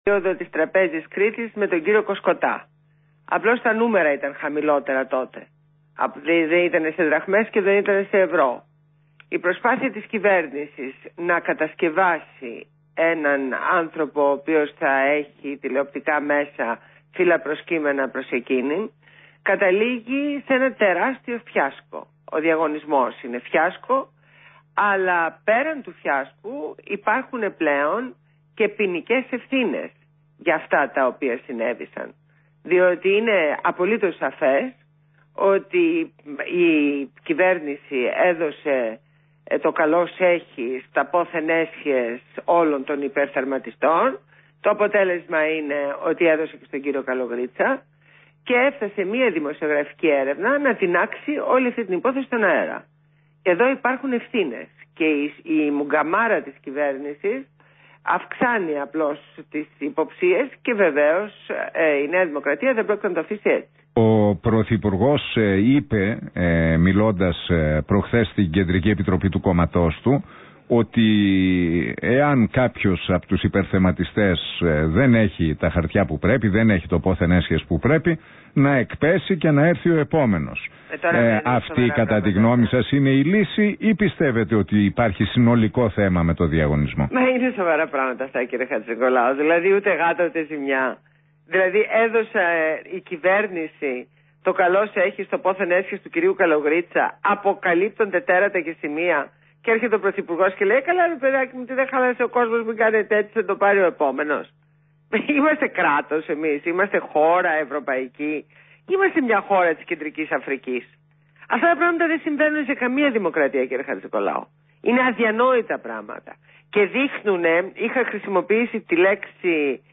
Συνέντευξη στο ραδιόφωνο του REALfm 98,9 στον Ν. Χατζηνικολάου.